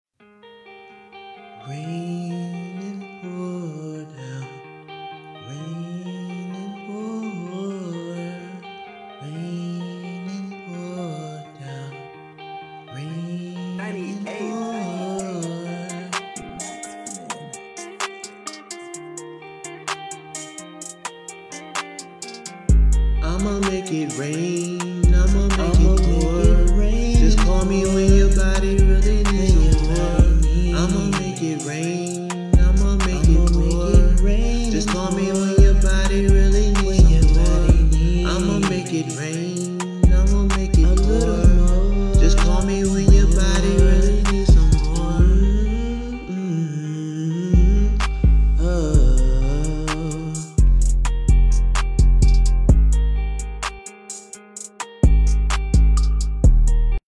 RnB
Laid Back R&B joint